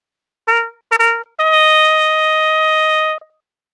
brass fanfare hps hpsModel reconstructed sms sms-tools synthesis sound effect free sound royalty free Memes